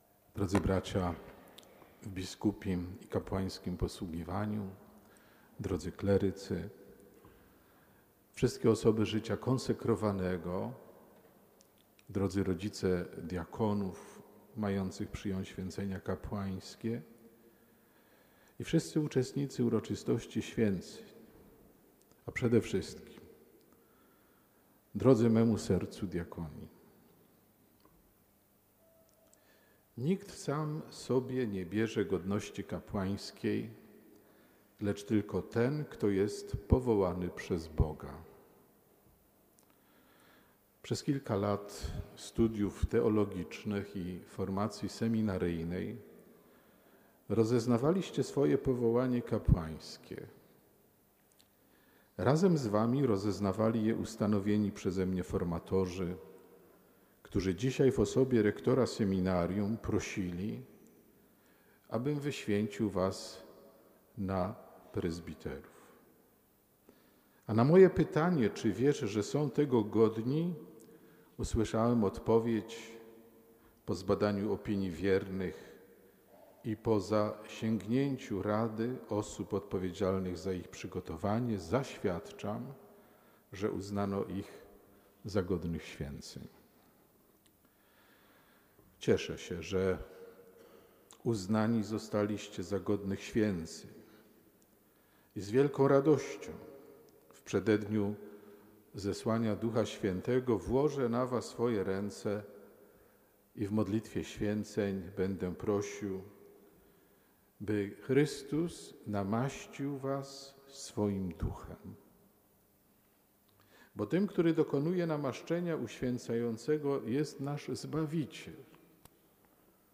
Prawdziwy pasterz realizuje siebie, buduje swoją wielkość poprzez wypełnianie misji, do której został powołany przez Chrystusa – mówił abp Józef Kupny we wrocławskiej katedrze w czasie Mszy św., tuż przed udzieleniem święceń prezbiteratu 11 diakonom.